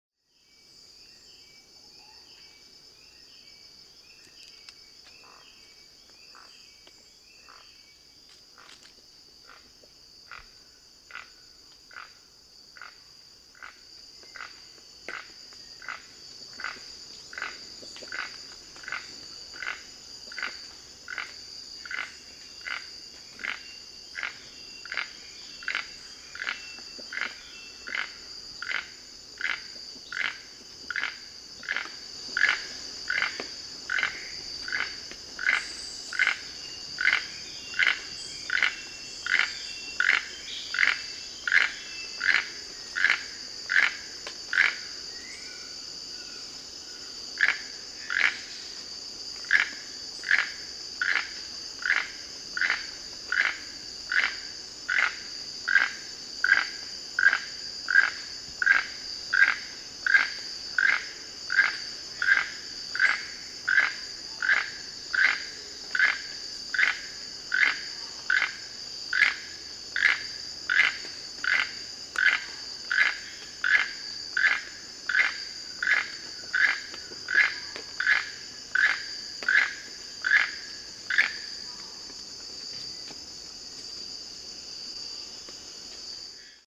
Costa Rica: Keel-billed Toucan (Ramphastos sulfuratus)